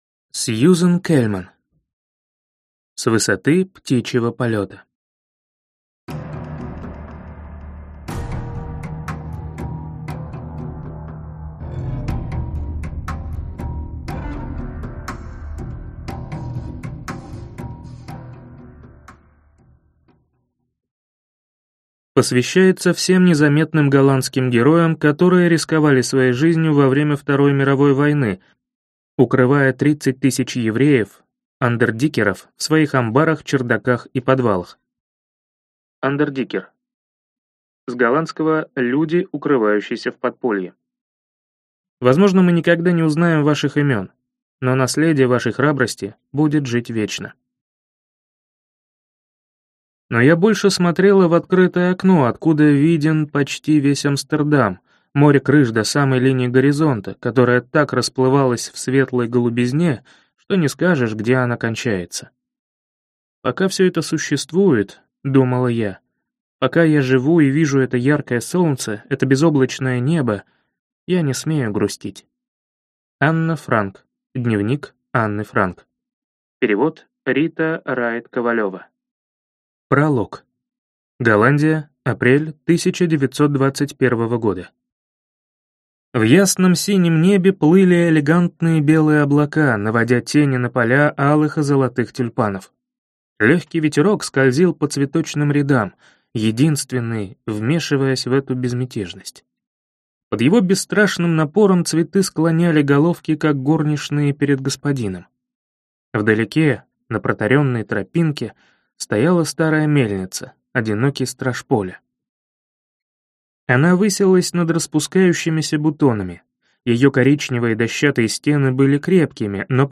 Аудиокнига С высоты птичьего полета | Библиотека аудиокниг